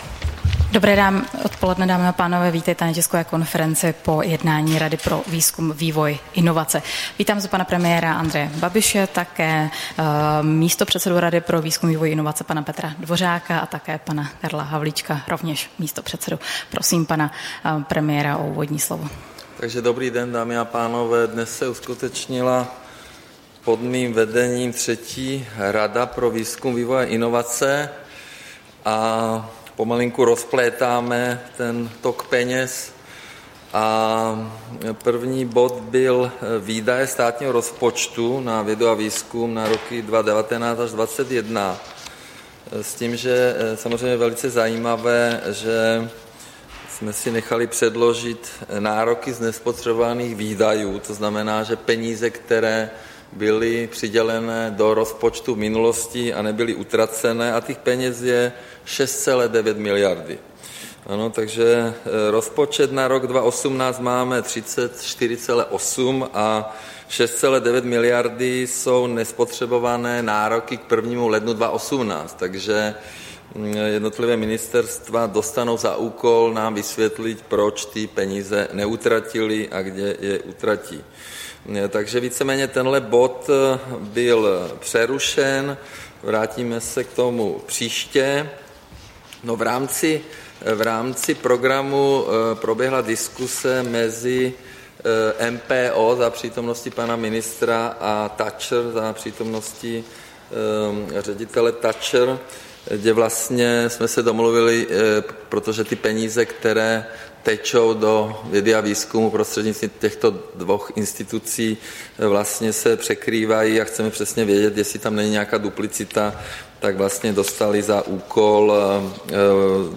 Tisková konference po jednání Rady pro výzkum, vývoj a inovace, 22. února 2018